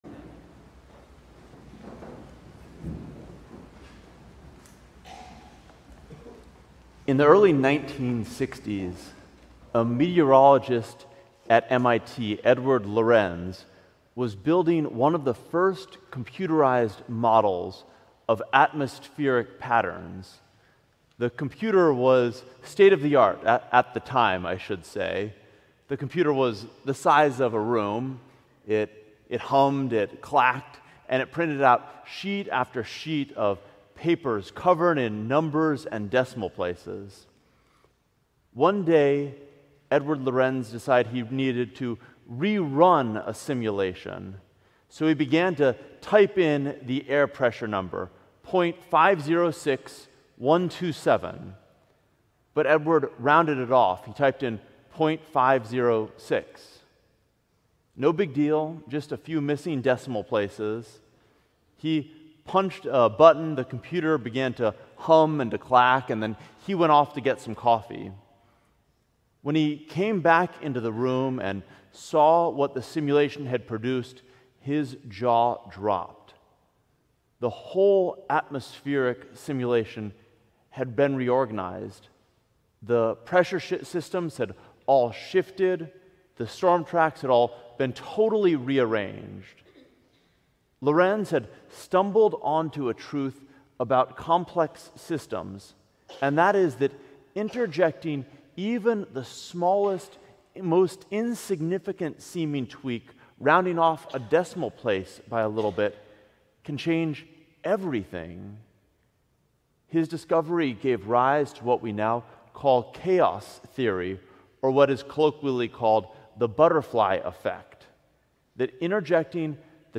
Sermon: The Source of Chaos - St. John's Cathedral